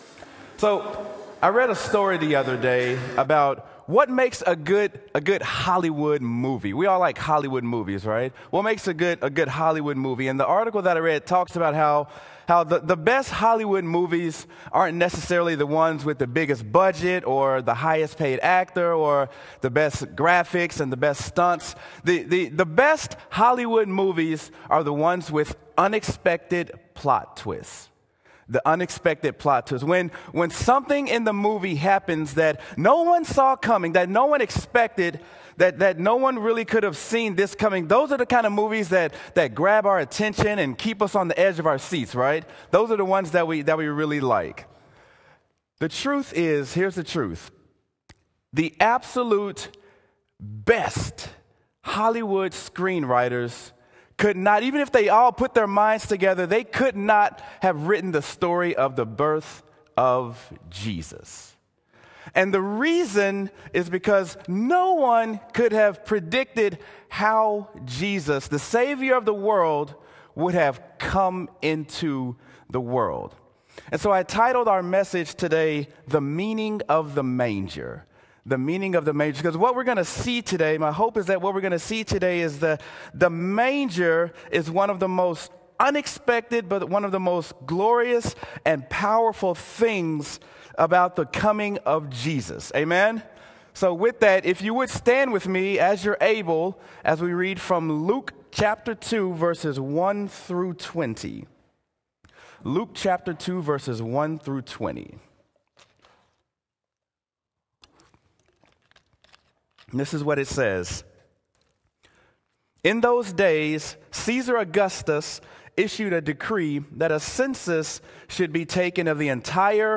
Sermon: He Has Come: Meaning of the Manger
sermon-he-has-come-meaning-of-the-manger.m4a